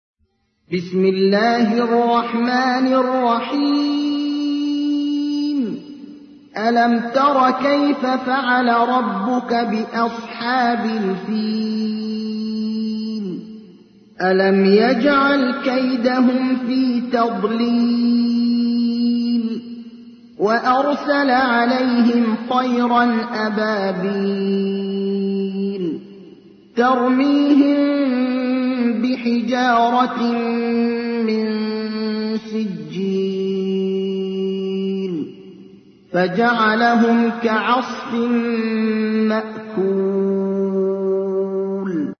تحميل : 105. سورة الفيل / القارئ ابراهيم الأخضر / القرآن الكريم / موقع يا حسين